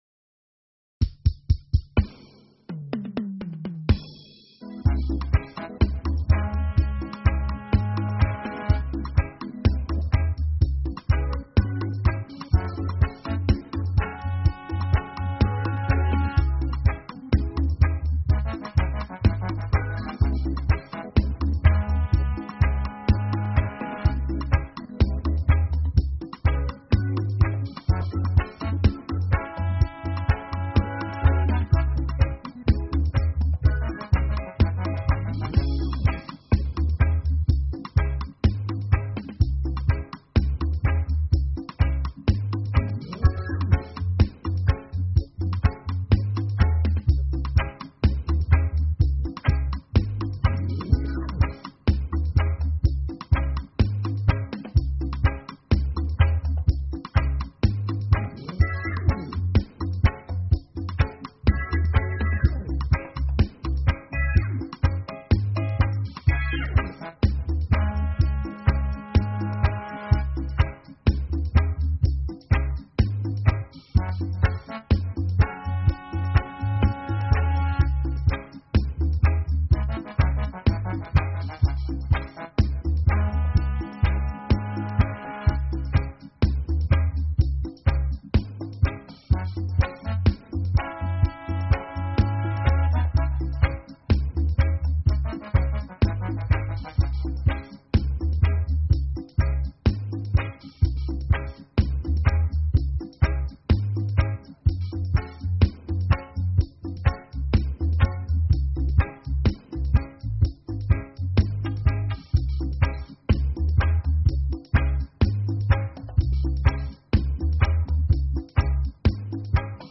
All instruments